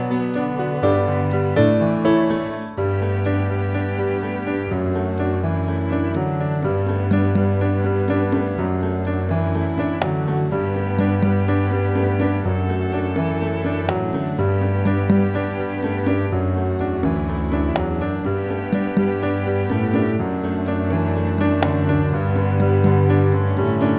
2 Semitones Up For Female